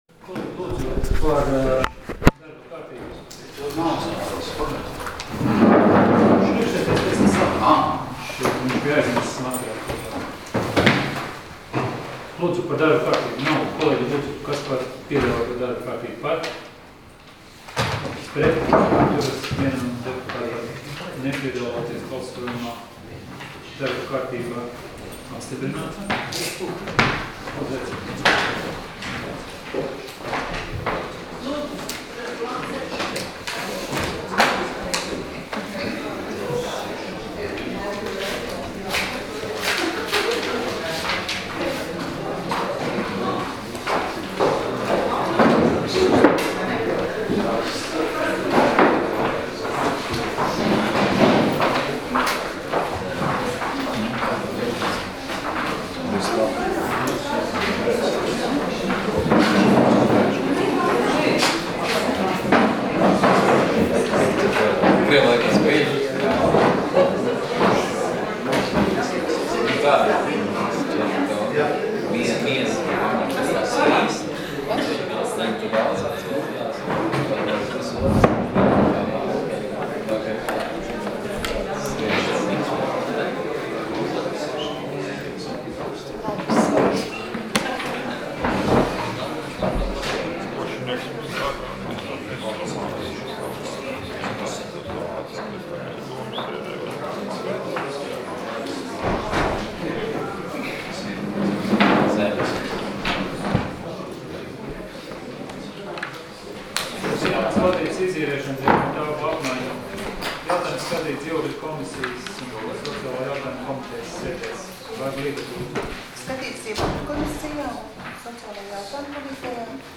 Domes sēdes 14.08.2015. audioieraksts